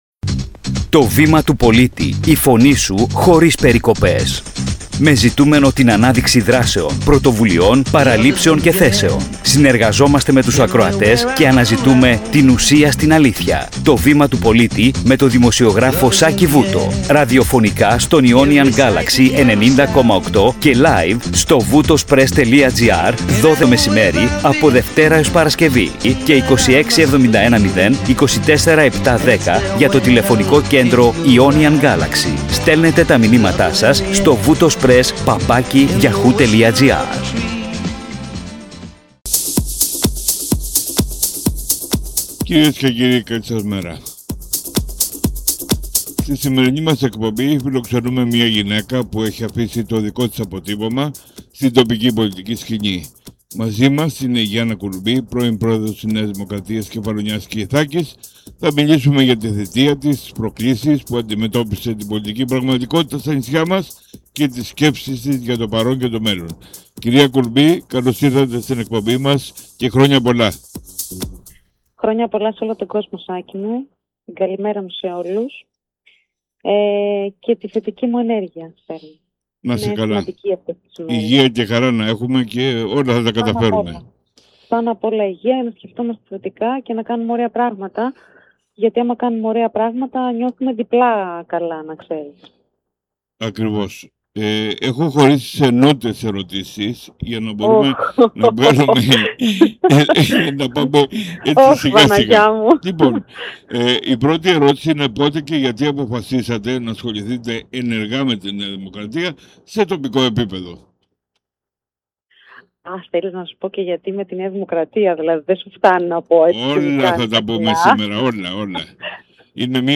Συνέντευξη εφ’ όλης της ύλης